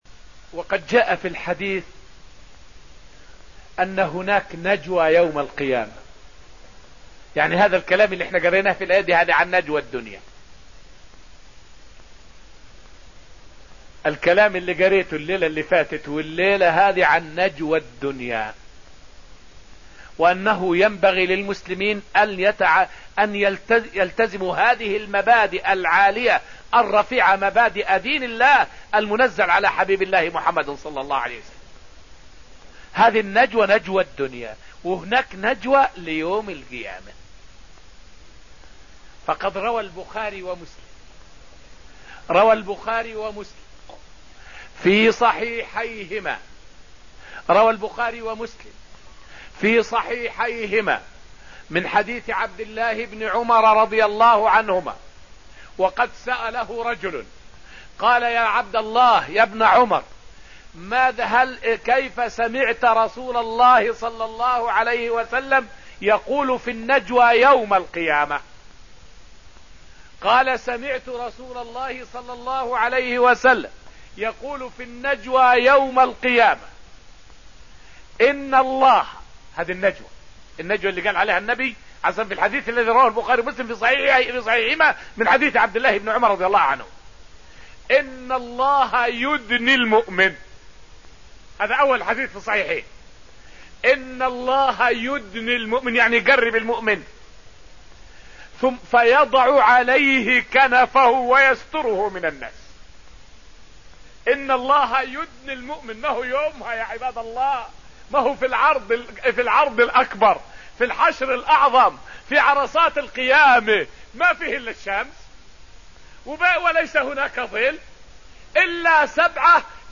فائدة من الدرس السادس من دروس تفسير سورة المجادلة والتي ألقيت في المسجد النبوي الشريف حول نجوى الله لعباده يوم القيامة.